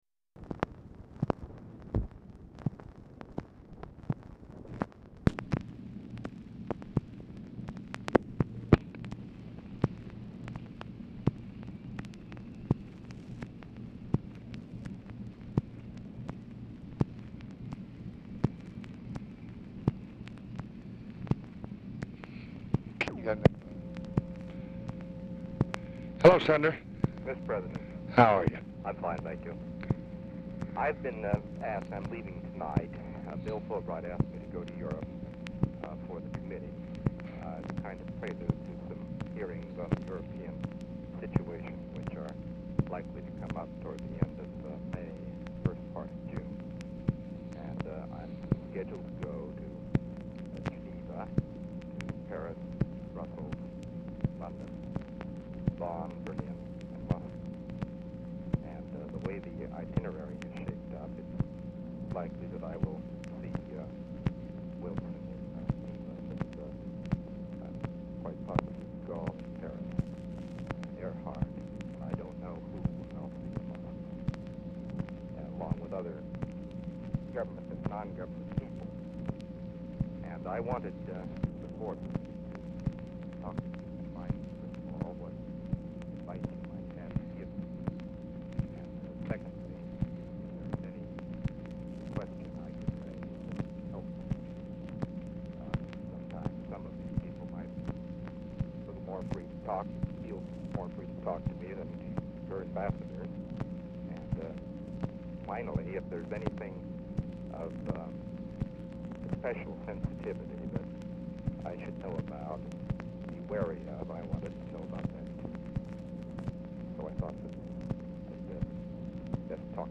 CHURCH ON HOLD 0:25, DIFFICULT TO HEAR
Format Dictation belt
Specific Item Type Telephone conversation Subject Congressional Relations Diplomacy Nato Ussr And Eastern Europe Western Europe